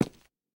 immersive-sounds / sound / footsteps / rails / rails-10.ogg
rails-10.ogg